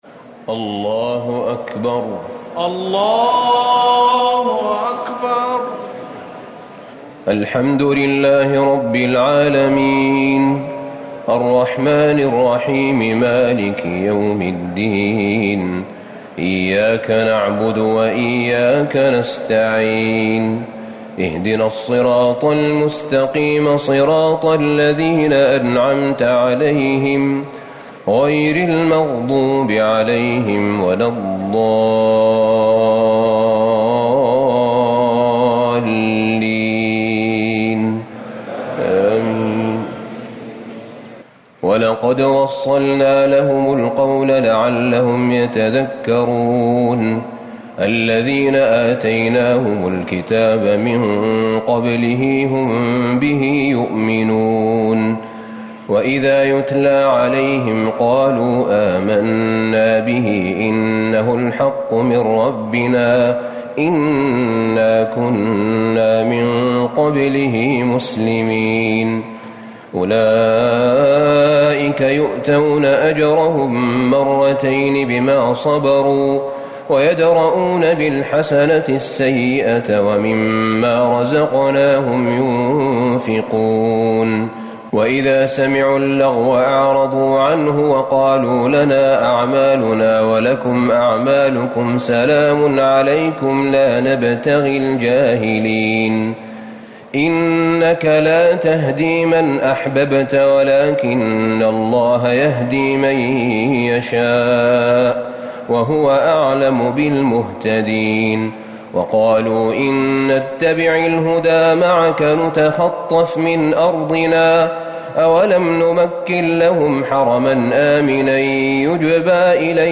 ليلة ١٩ رمضان ١٤٤٠ من سورة القصص ٥١ - العنكبوت ٤٥ > تراويح الحرم النبوي عام 1440 🕌 > التراويح - تلاوات الحرمين